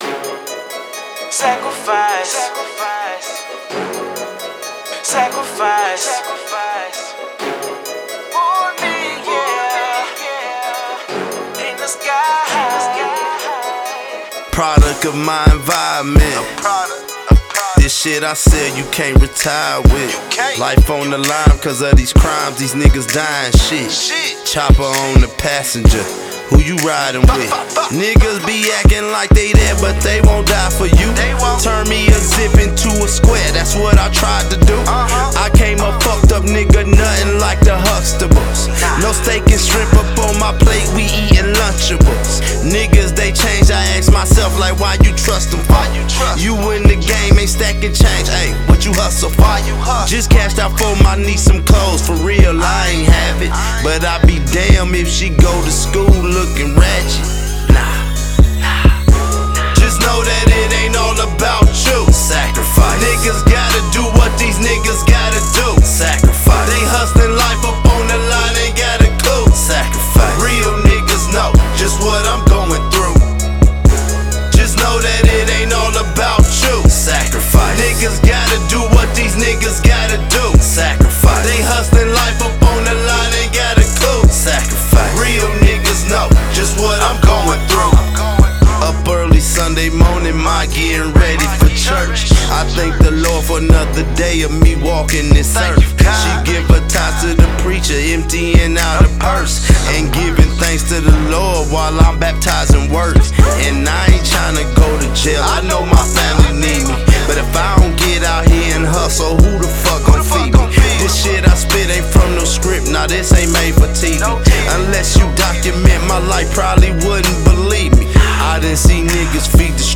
Description : raw gritty reality music.